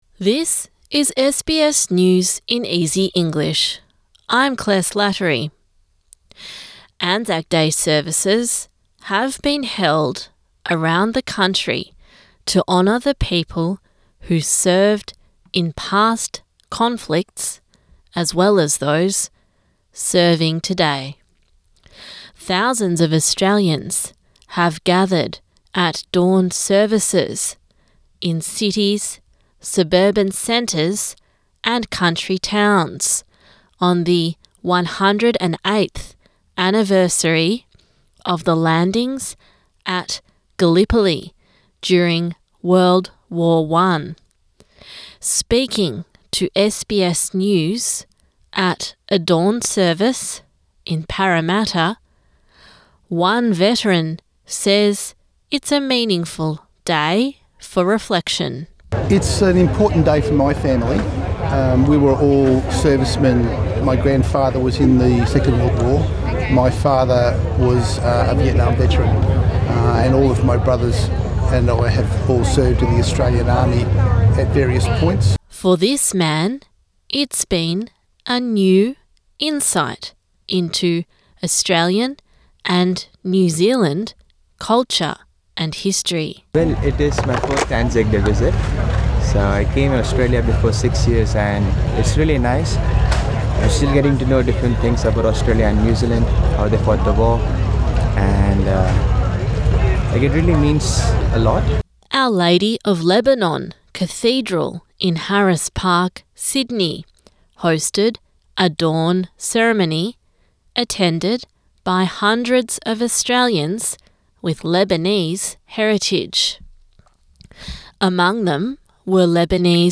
A daily five-minute news wrap for English learners and people with disability.